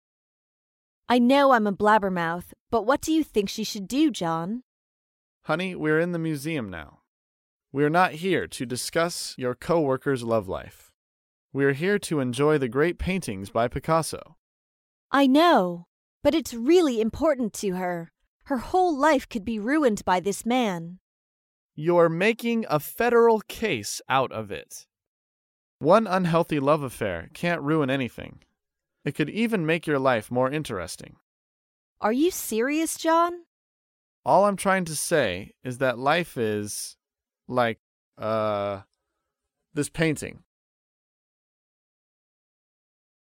在线英语听力室高频英语口语对话 第164期:不伦之恋的听力文件下载,《高频英语口语对话》栏目包含了日常生活中经常使用的英语情景对话，是学习英语口语，能够帮助英语爱好者在听英语对话的过程中，积累英语口语习语知识，提高英语听说水平，并通过栏目中的中英文字幕和音频MP3文件，提高英语语感。